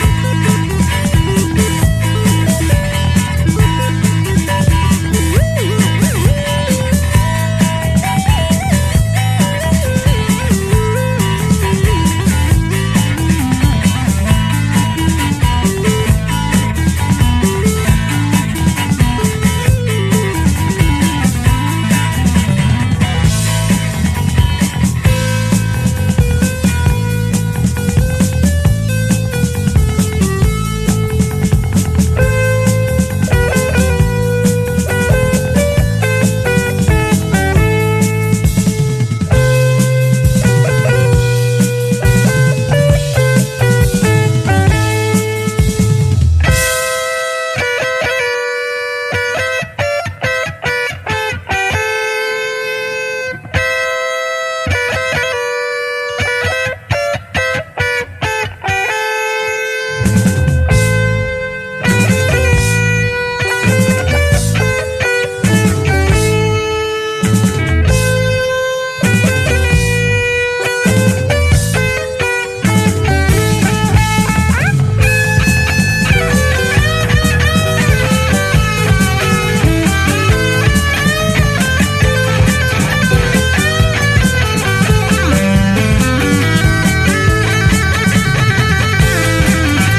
サイケデリックな轟音ギターとヘヴィなヴォーカルで暴れ回る
ガツンと響く重厚オルタナ・サウンドと怒号をぶつける